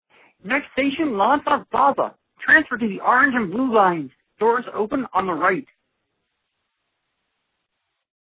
Metro announcement - Too excited?